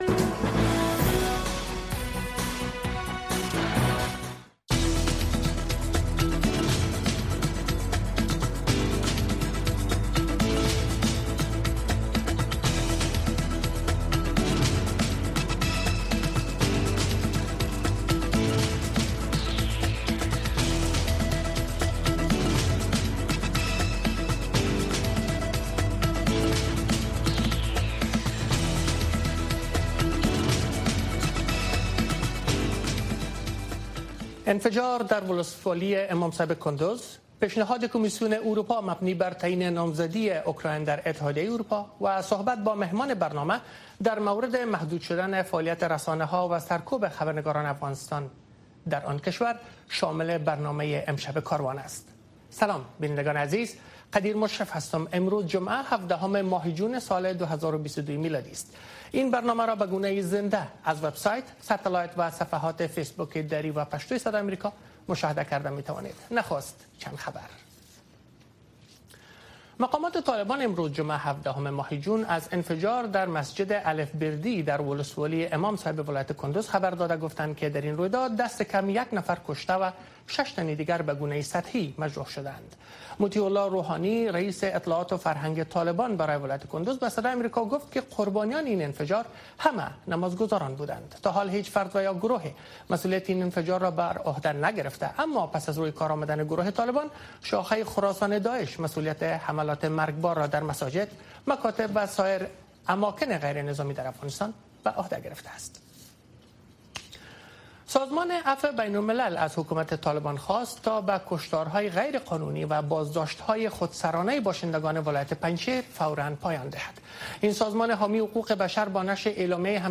برنامۀ خبری شامگاهی